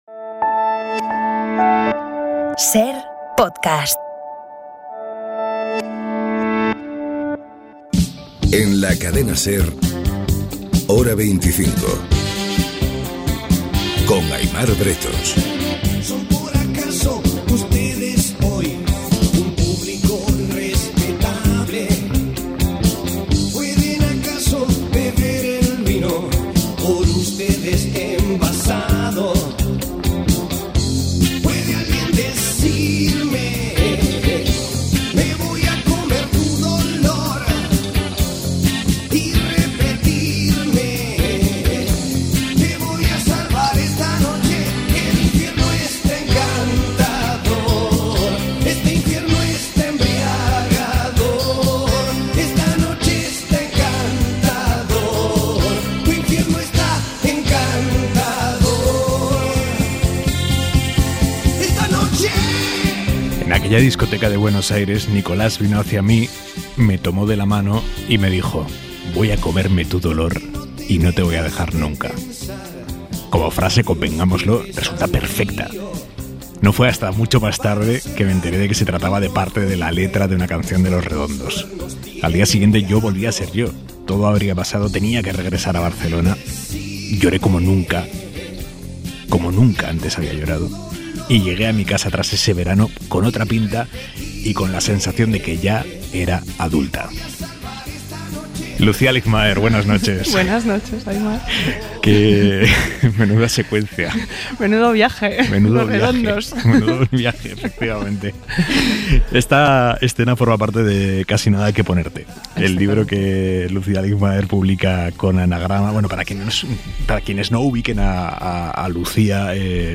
Aimar Bretos ha entrevistado a la periodista, escritora, crítica cultural y codirectora del podcast 'Deforme Semanal'